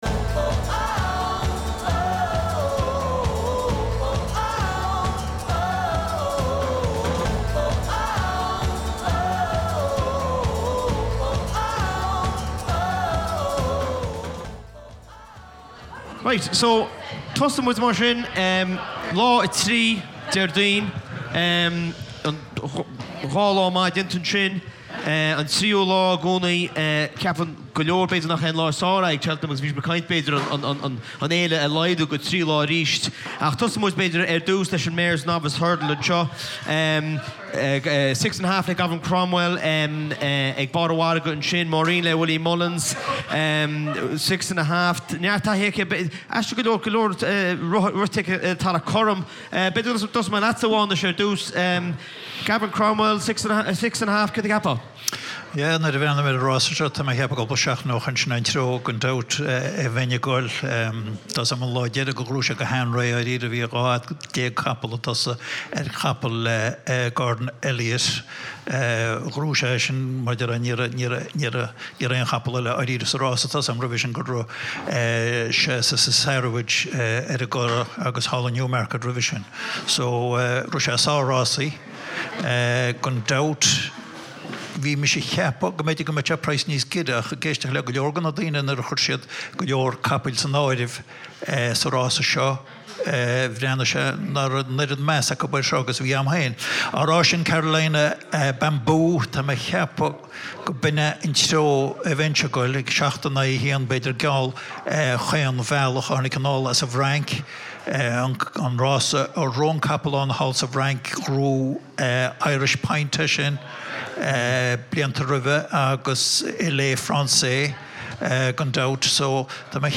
Bhí oíche den scoth againn ag an seó beo a bhí ag an Spota Dubh thar an deireadh seachtaine agus muid ag breathnú ar Fhéile Rásaíochta Cheltenham.